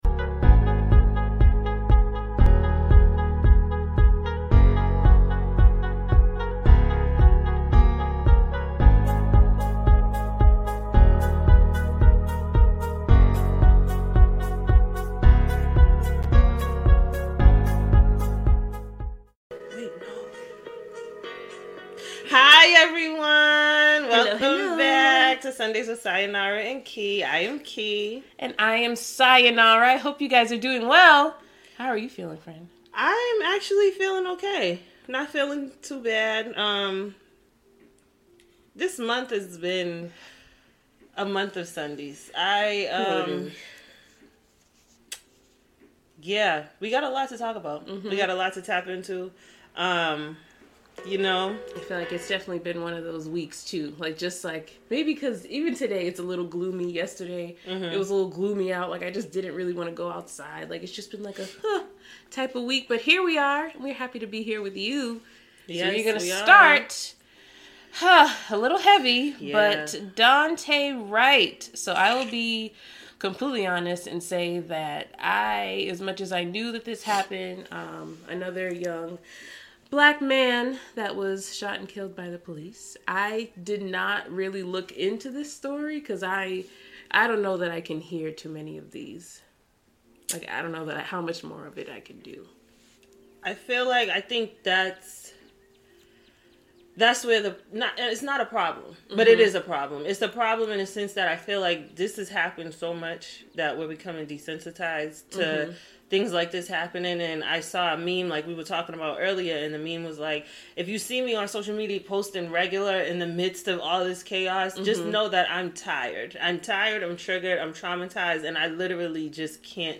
And had an acapella moment